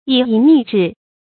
以意逆志 yǐ yì nì zhì
以意逆志发音
成语注音ㄧˇ ㄧˋ ㄋㄧˋ ㄓㄧˋ